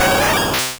Cri de Pyroli dans Pokémon Rouge et Bleu.